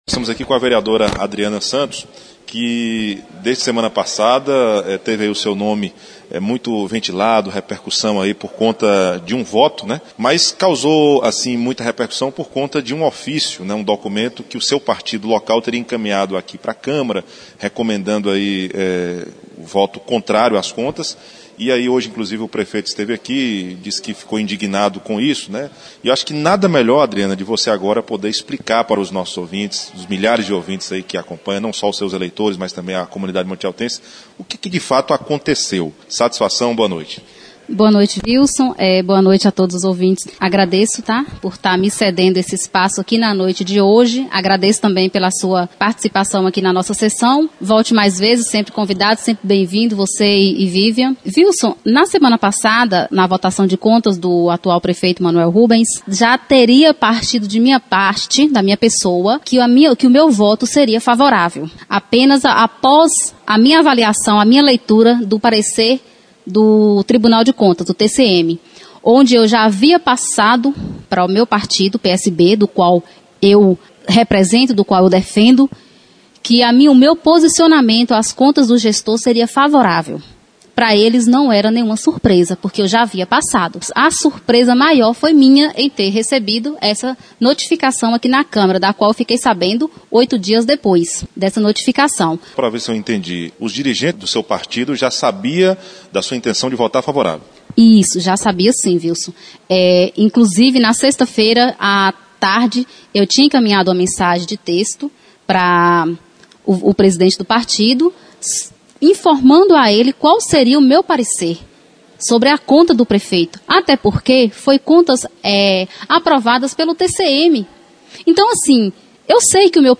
Palmas de Monte Alto: Em entrevista, vereadora Adriana Santos fala sobre polêmica envolvendo o seu voto a favor das contas do prefeito Manoel Rubens; ouça